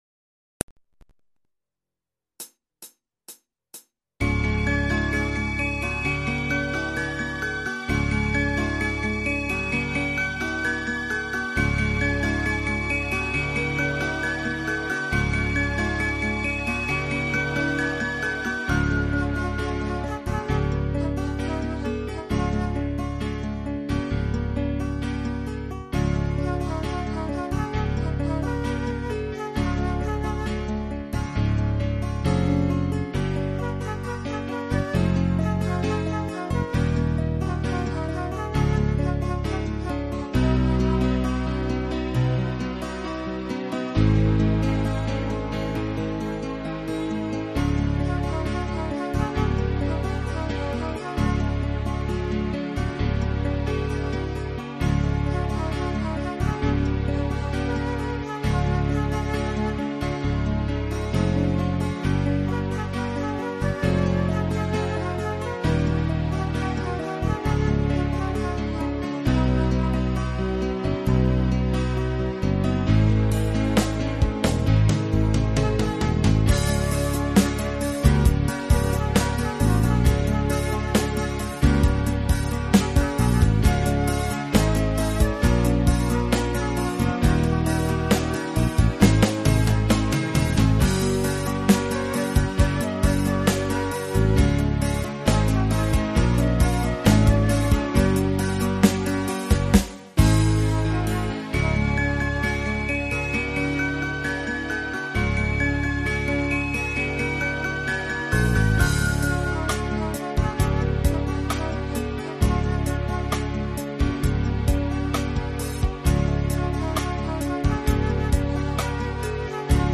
PRO MIDI INSTRUMENTAL VERSION